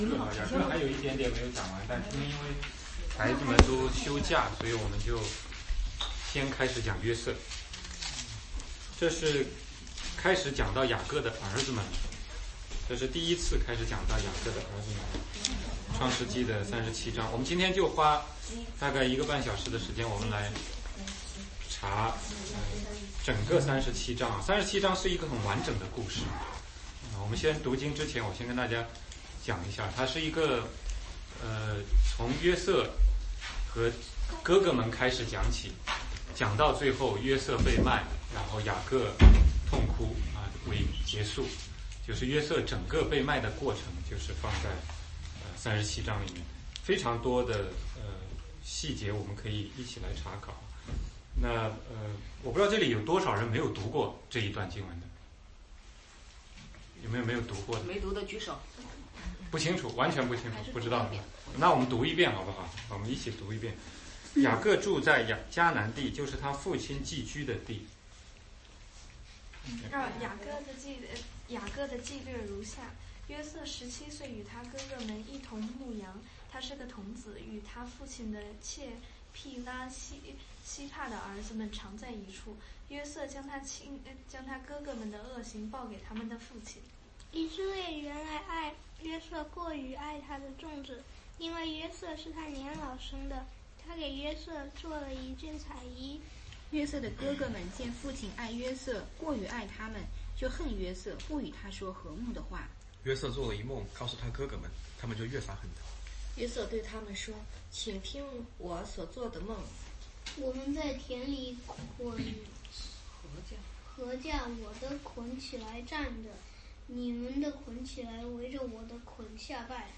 16街讲道录音 - 约瑟被卖的故事